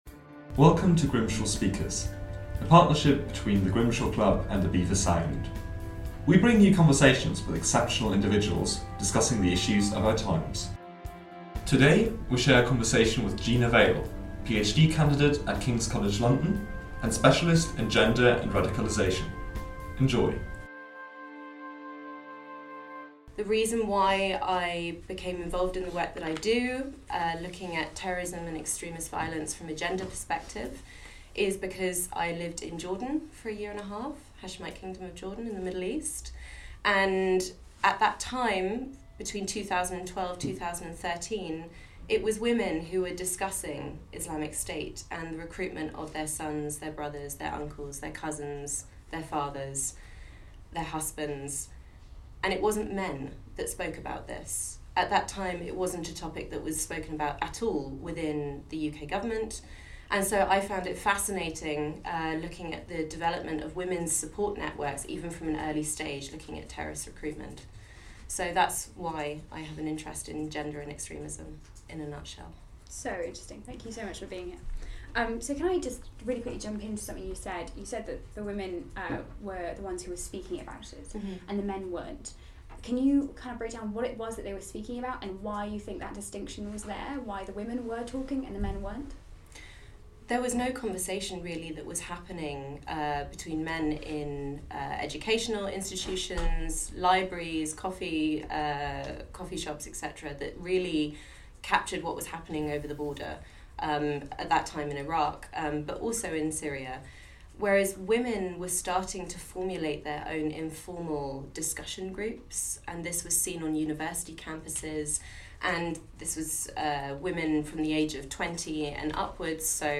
At a recent coffee conversation